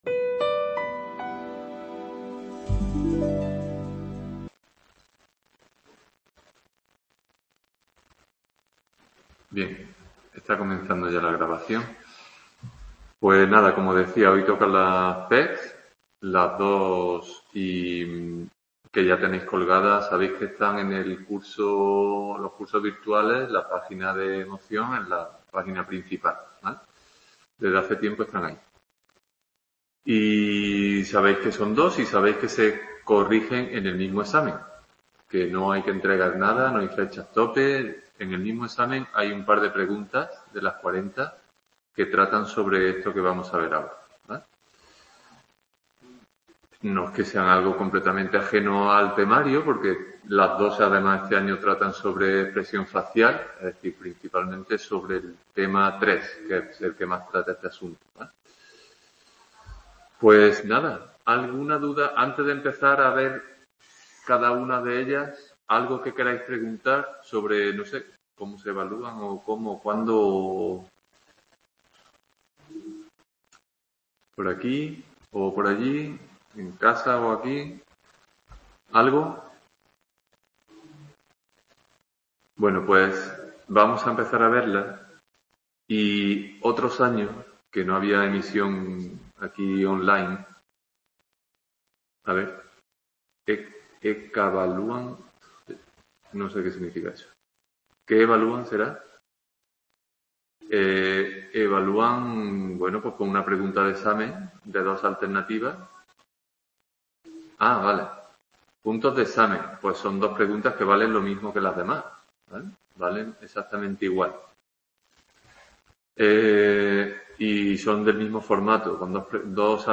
PECs 2022 Description Tutoría dedicada a las dos prácticas (PECs) de Psicología de Emoción del curso 2022. Ambas están centradas en la expresión facial de las emociones.